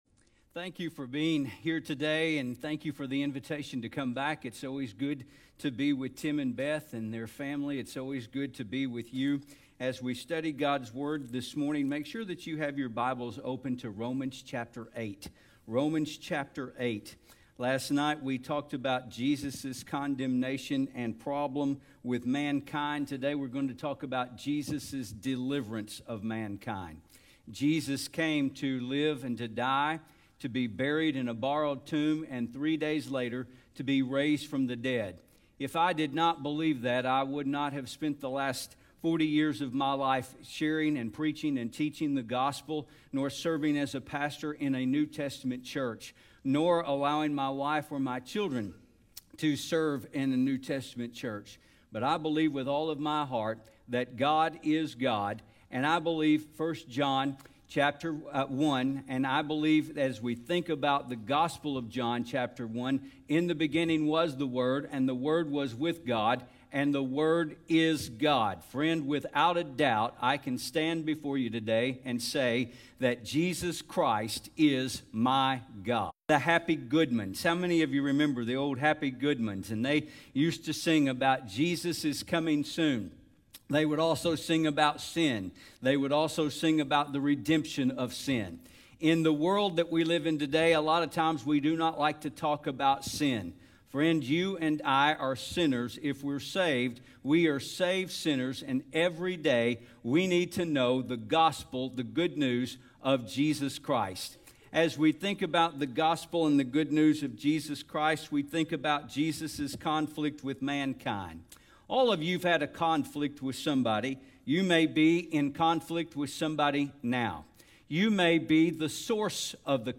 Sermons | Mount Vernon Baptist Church